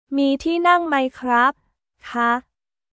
ミー ティーナン マイ クラップ／カ？